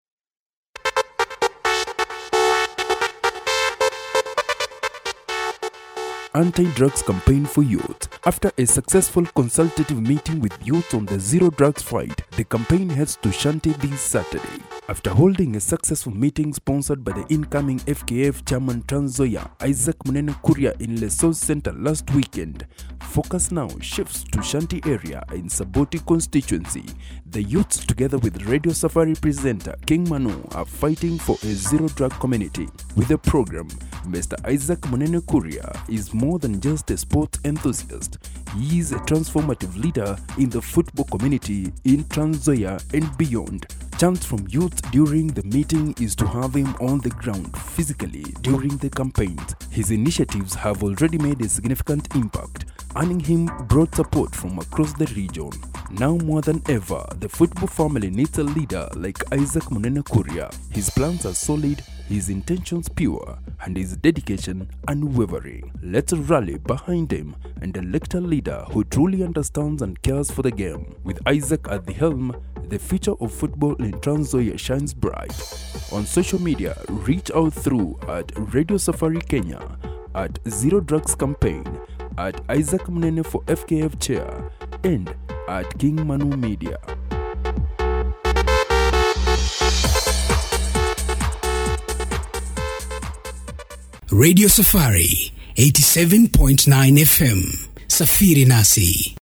Commercial Demo
BaritoneBassDeep
AuthoritativeCorporateExperiencedAccurateConversational